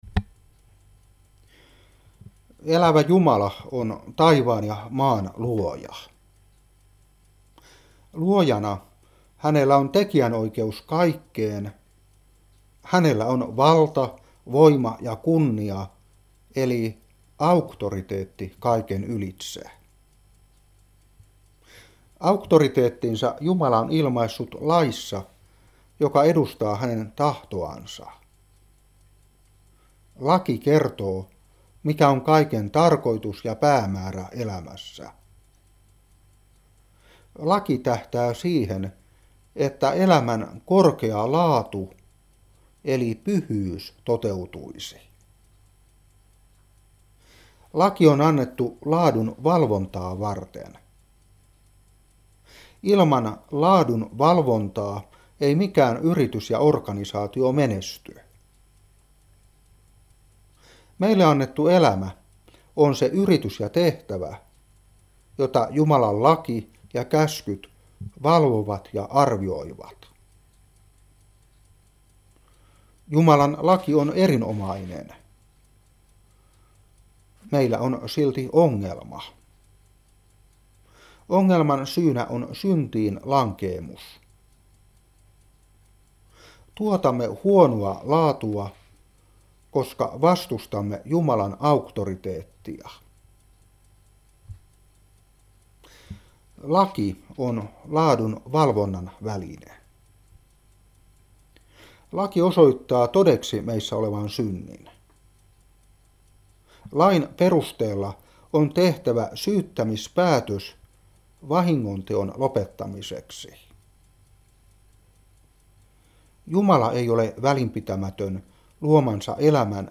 Opetuspuhe 2019-3. Jes.1:18. Kol.2:13-14. Ef.1:3-7.